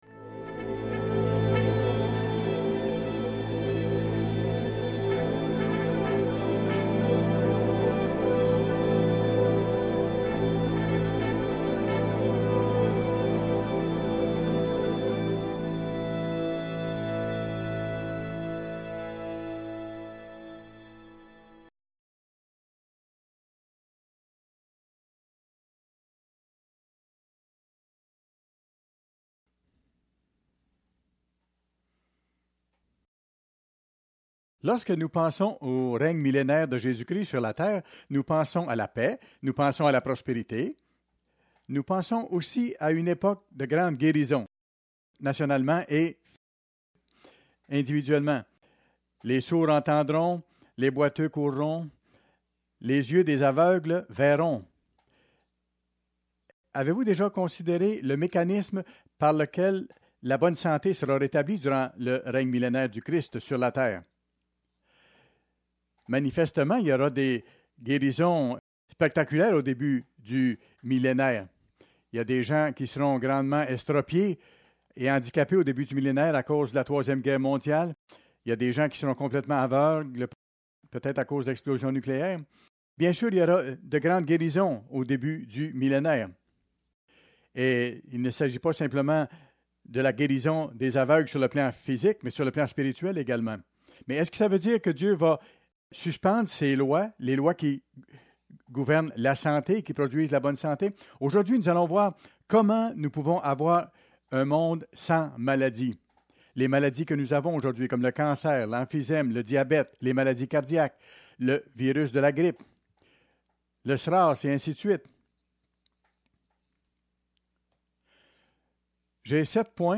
Fête des Tabernacles, 5ème jour